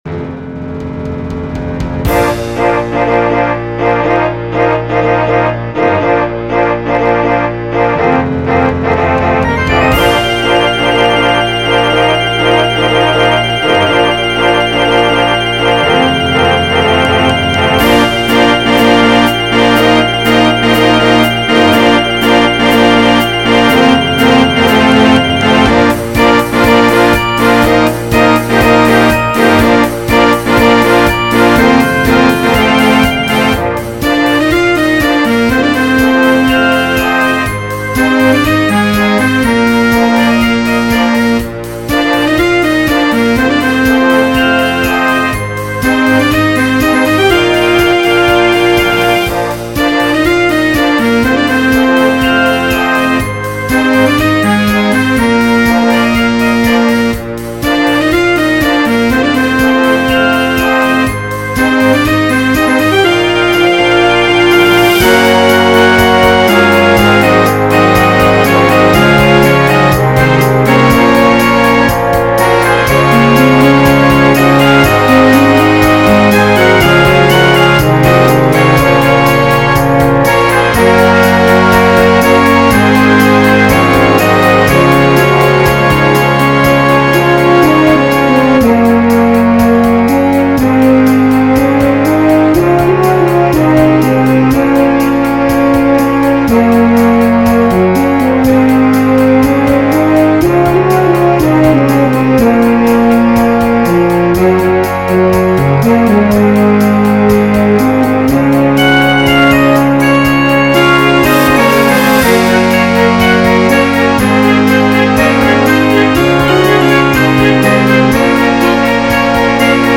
für großes Blasorchester…
Moderne Blasmusik